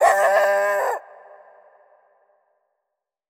dog-dataset
dogs_0044.wav